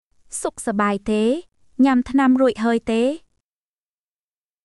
解説→→（ソック（良い） サバーイ（元気） テー（ですか？） ニャム（飲む／食べる） タナム（薬） ルイハゥイ（すでに／もう済んだ） テー？（ですか？））
当記事で使用された音声（クメール語および日本語）は全てGoogle翻訳　および　Microsoft TranslatorNative Speech Generation、©音読さんから引用しております。